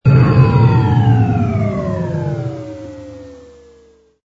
engine_ku_cruise_stop.wav